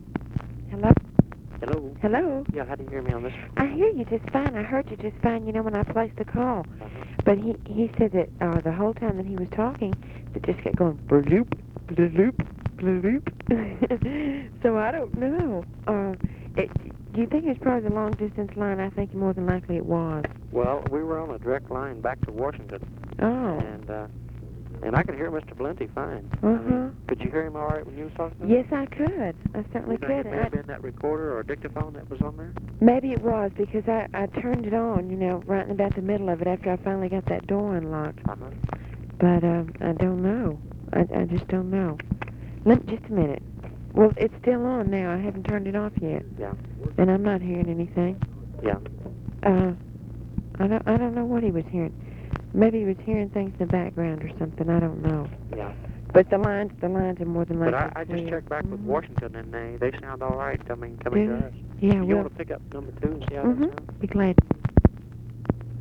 Conversation with OFFICE SECRETARY and (possibly) SIGNAL CORPS OPERATOR, February 7, 1964